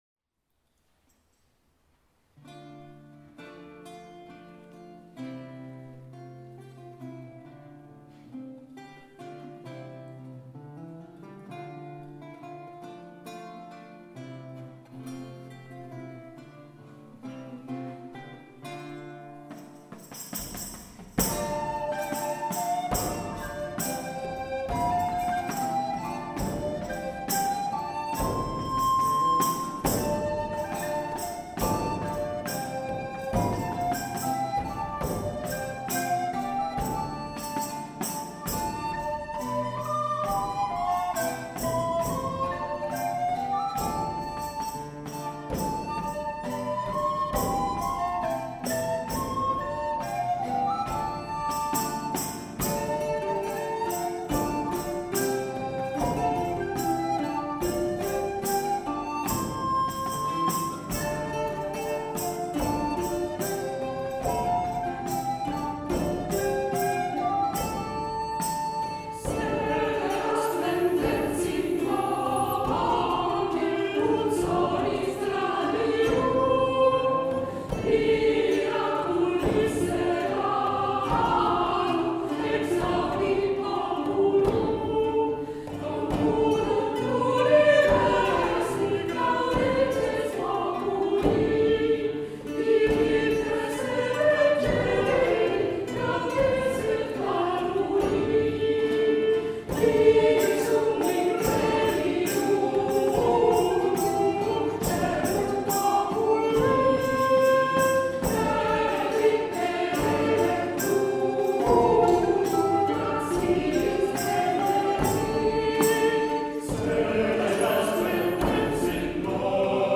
2023 | Ensemble vocal Alter-Écho
• 🎼 11 juin, St-Sulpice: Concert , Œuvres chorales spirituelles médiévales, baroques, et modernes :
Œuvres du Moyen-Âge: